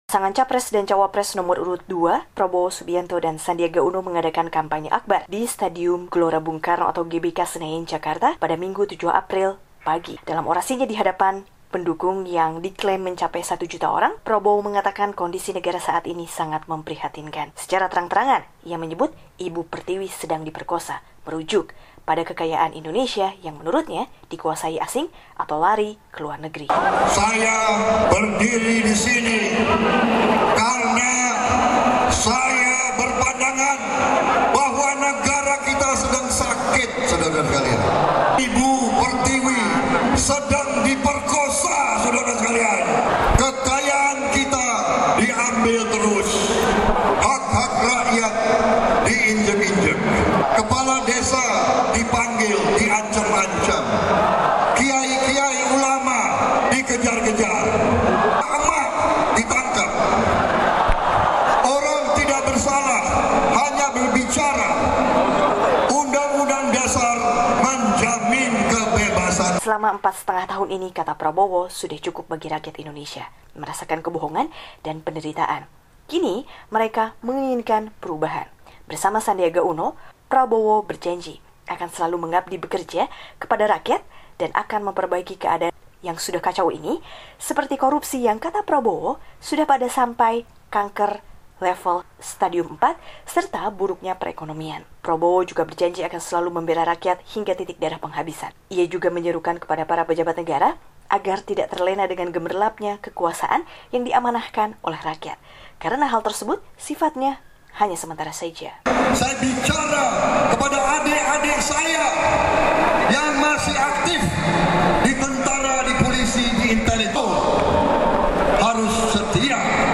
Dalam kampanye akbar Minggu (7/4) di GBK Jakarta, Capres nomor urut dua Prabowo Subianto mengatakan Indonesia tercinta sedang diperkosa oleh rezim kekuasaan pada saat ini.